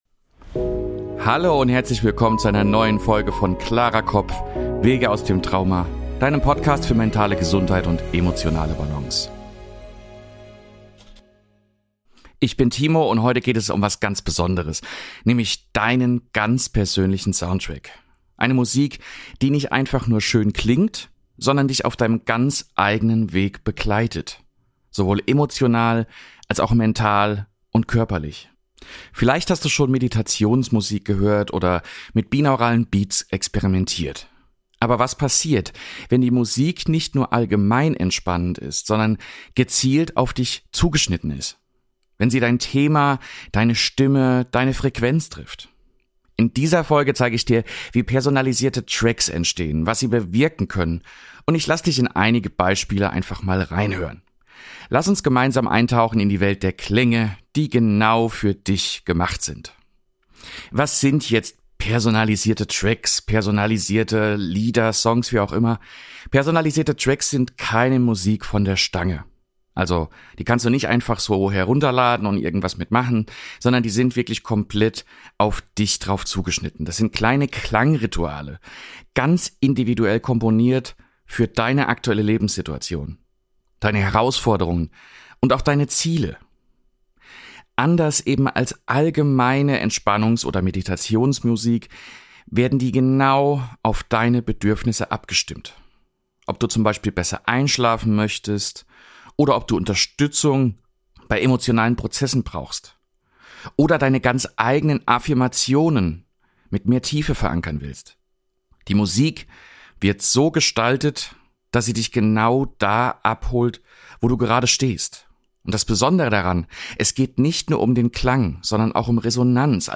exklusive Klangbeispiele, die zeigen, wie individuell Musik wirken